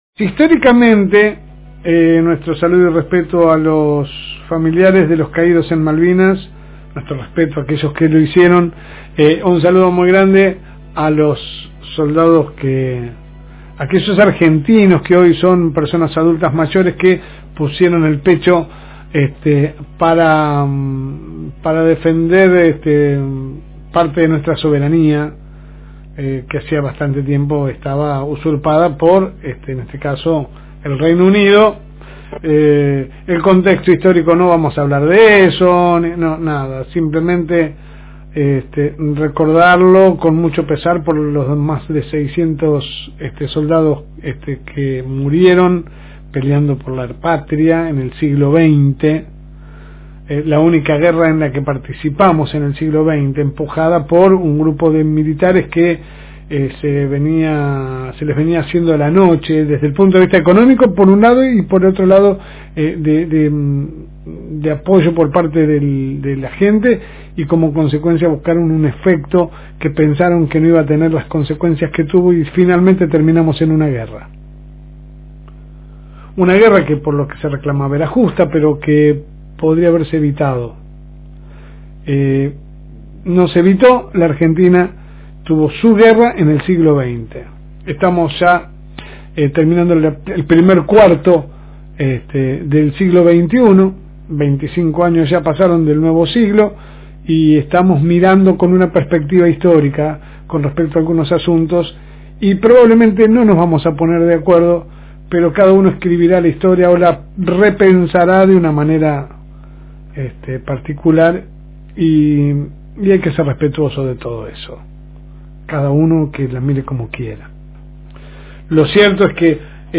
Editorial LSM